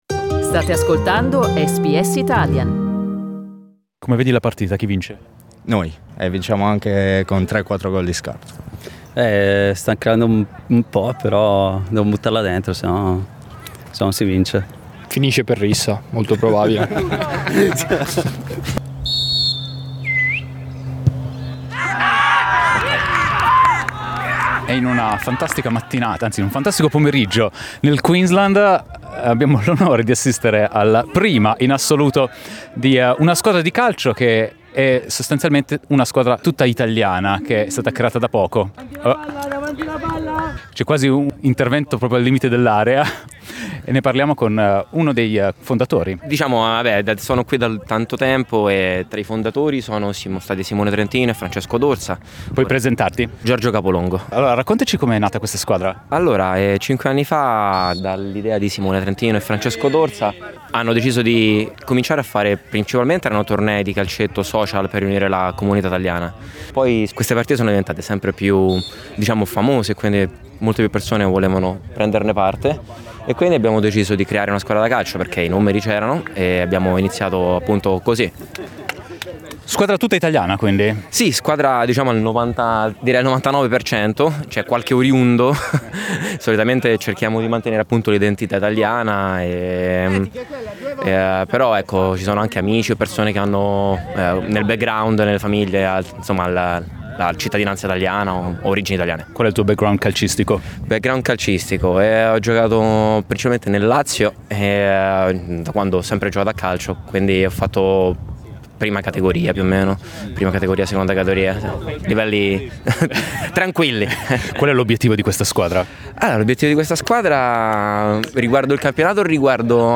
SBS Italian è andata a trovarli in trasferta, ascolta come è andata la prima giornata di campionato della NIG.